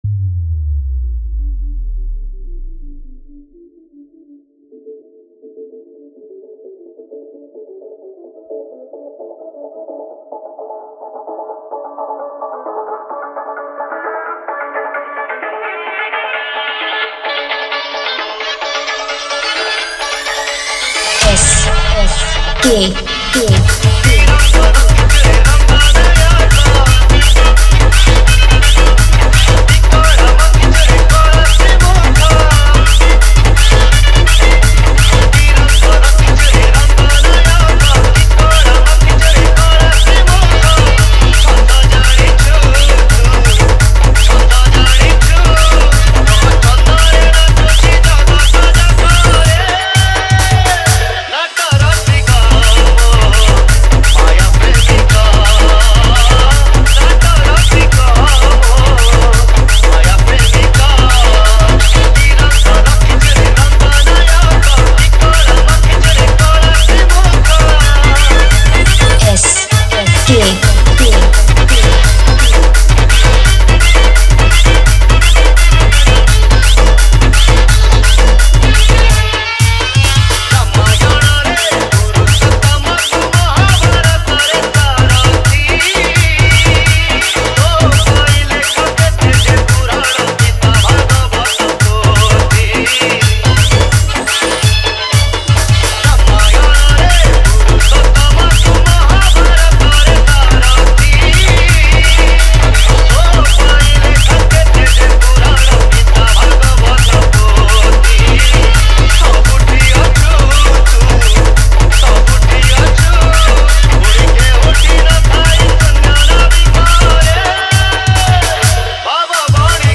Category:  Odia Bhajan Dj 2021